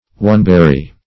oneberry - definition of oneberry - synonyms, pronunciation, spelling from Free Dictionary Search Result for " oneberry" : The Collaborative International Dictionary of English v.0.48: Oneberry \One"ber`ry\, n. (Bot.) The herb Paris.